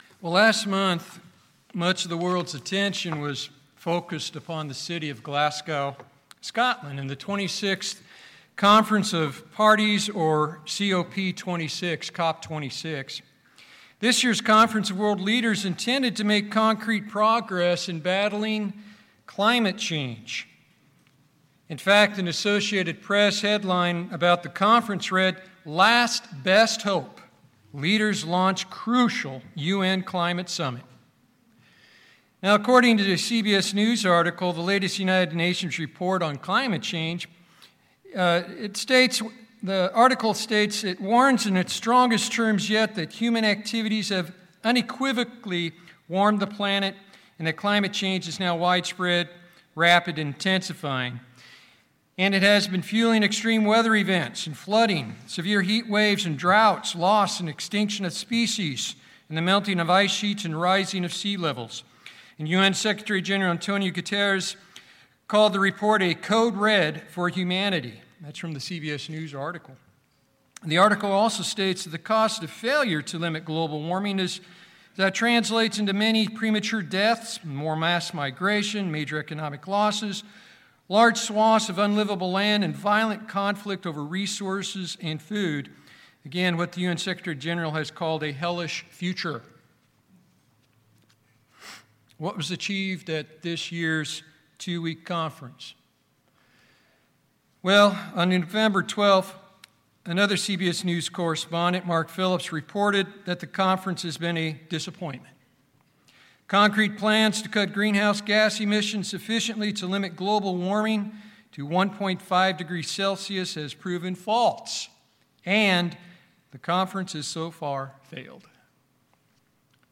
Do you trust God in faith and full confidence or too much in yourself? These are the questions addressed in this sermon.